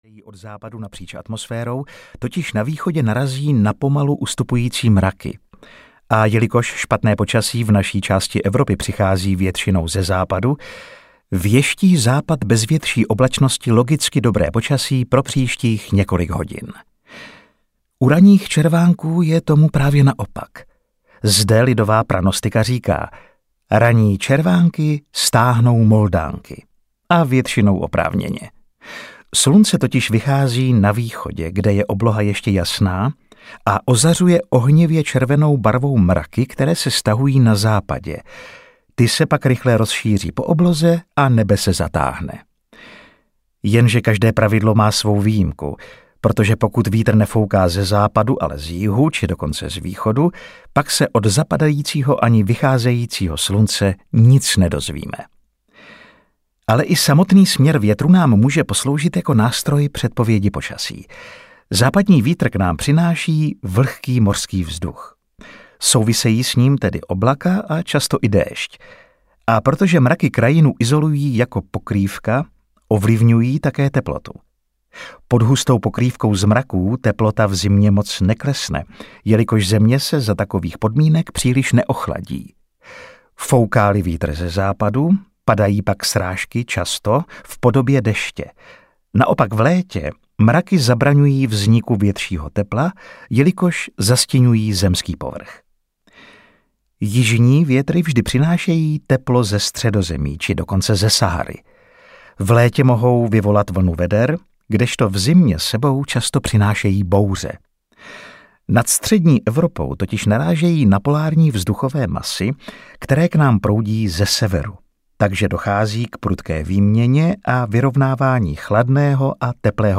Přírodě na stopě audiokniha
Ukázka z knihy